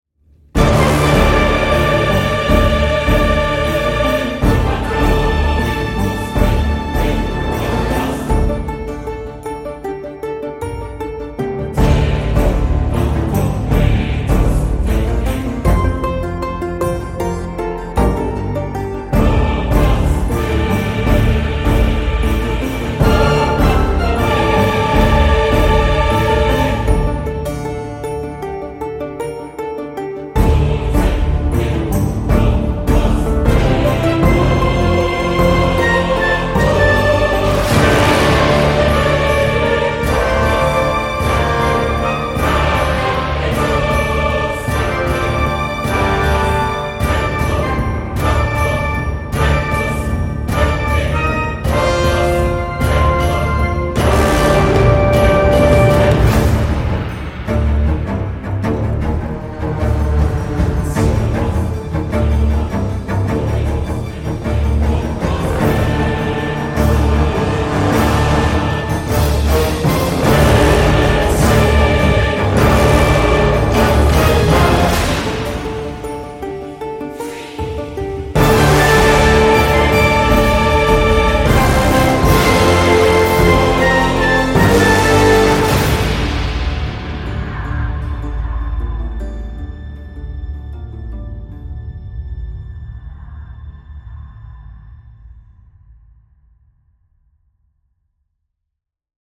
Énergique mais épuisant parfois.